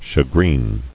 [sha·green]